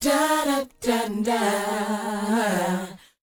DOWOP D#DU.wav